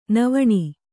♪ navaṇi